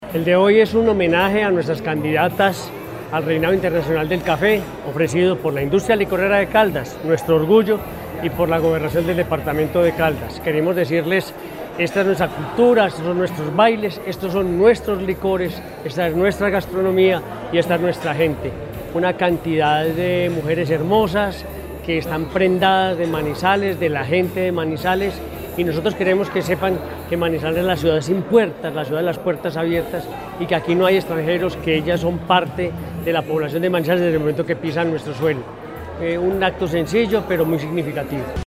Henry Gutiérrez Ángel, gobernador de Caldas.